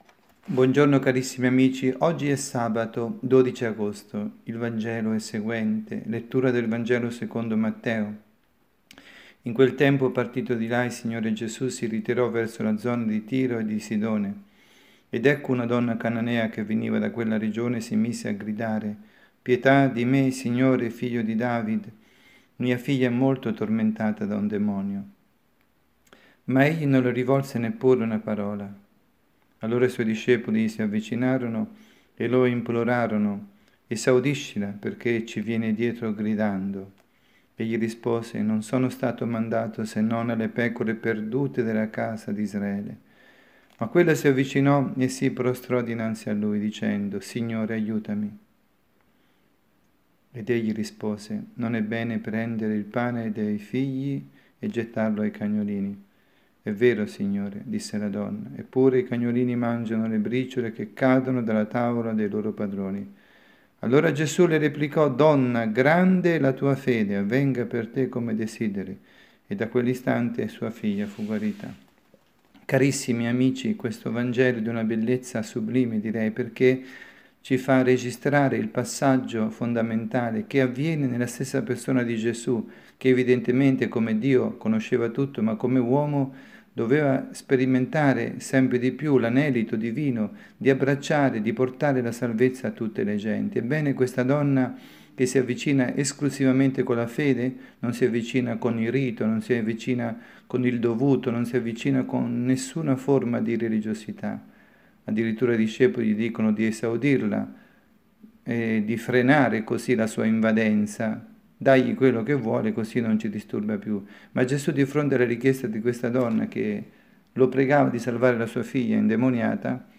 Omelia
dalla Parrocchia S. Rita, Milano